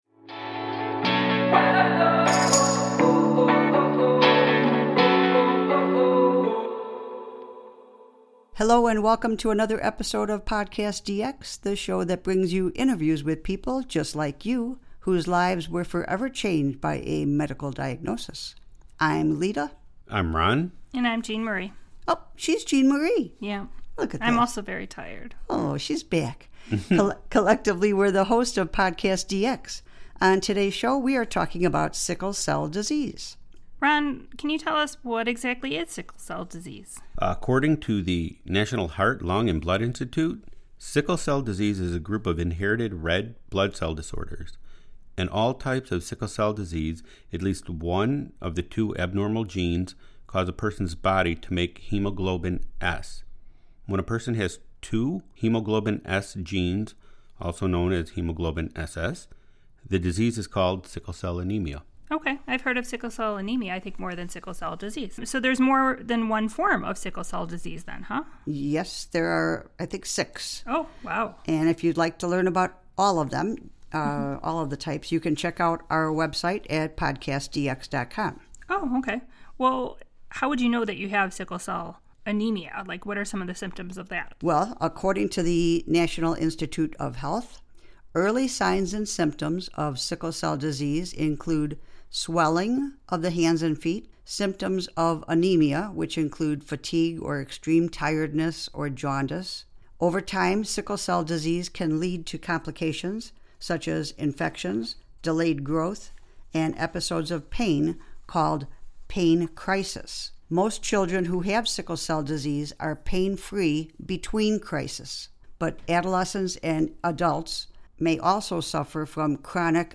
We normally interview a guest but will do a forum among ourselves.